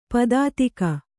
♪ padātika